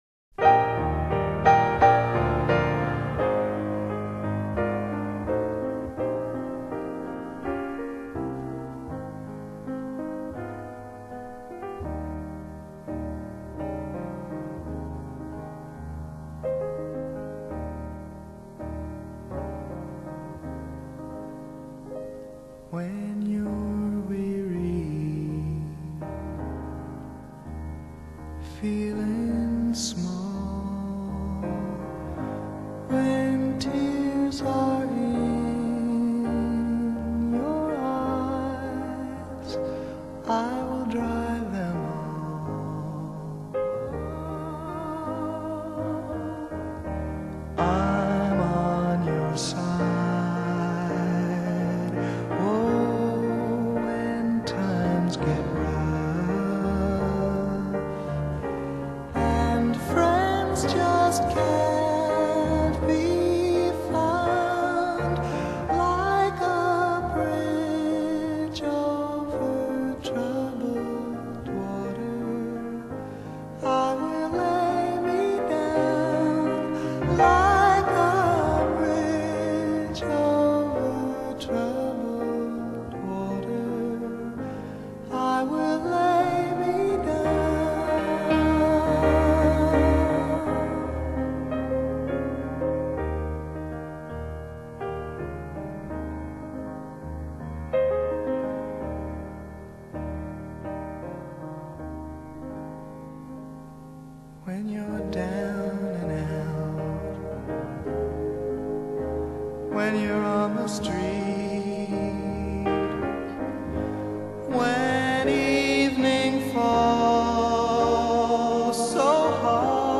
Genre: Folk-Rock, Soft Rock